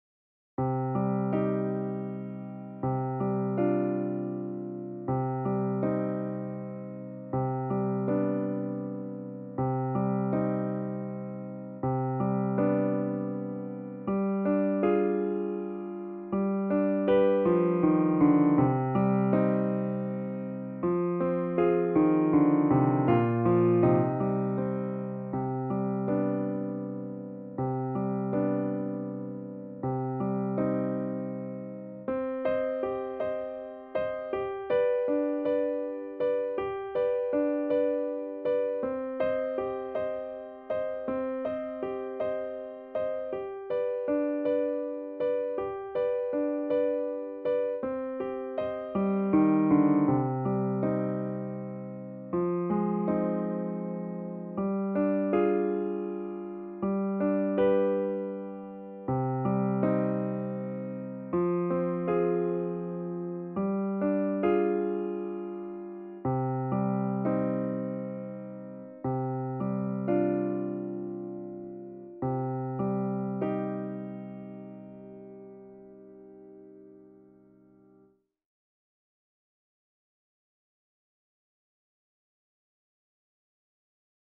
DIGITAL SHEET MUSIC - FLUTE with PIANO ACCOMPANIMENT
Flute Solo, Classical, Offers Flute with Piano accompaniment
piano with slower practice version and faster performance